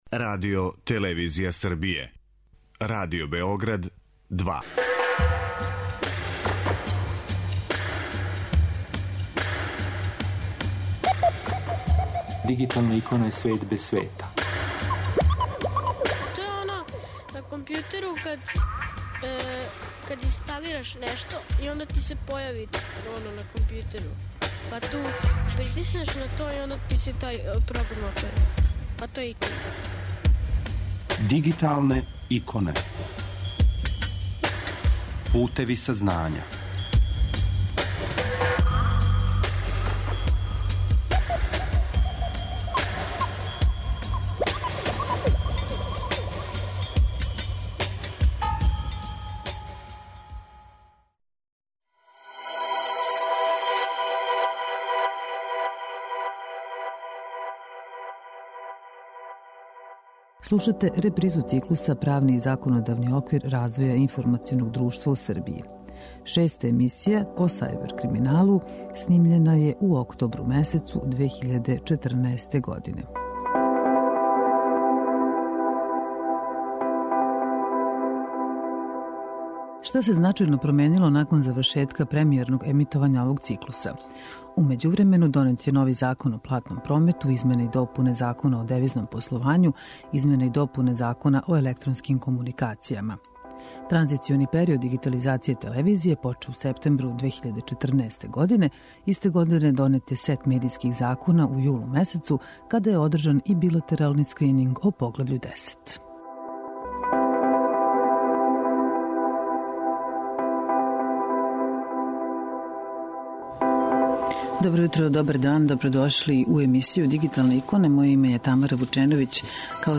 Ово су нека од питања на која смо одговоре потражили у разговору са посебним тужиоцем за високотехнолошки криминал и чланом Комисије за спровођење Националне стратегије реформе правосуђа за период 2013-2018. године, господином Бранком Стаменковићем.